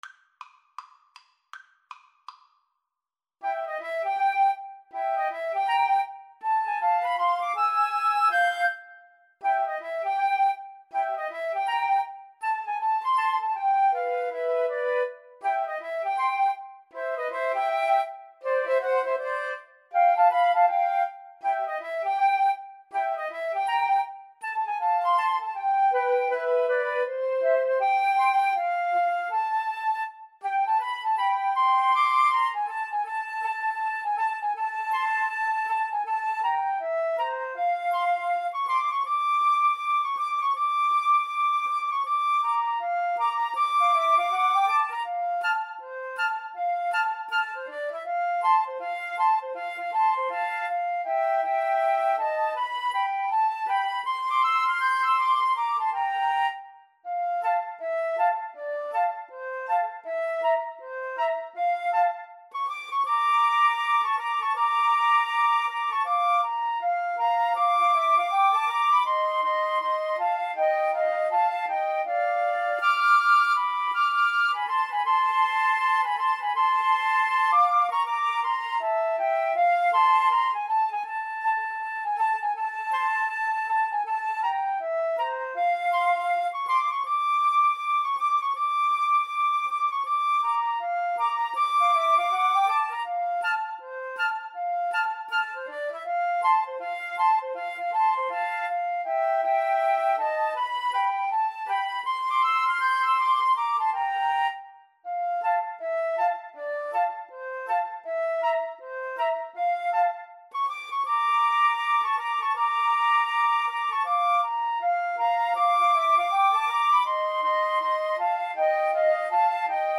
4/4 (View more 4/4 Music)
Flute Trio  (View more Intermediate Flute Trio Music)
Jazz (View more Jazz Flute Trio Music)